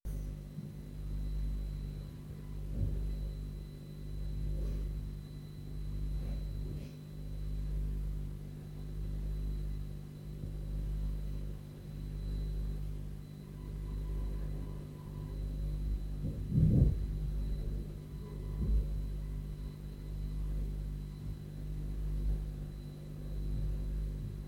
1. Bruit de craquements depuis mon home studio ;
L'écoute sur le logiciel Audition montre un bruit de choc long comme on peut voir.
1-bruit-enceinte-studio-prise-studio.wav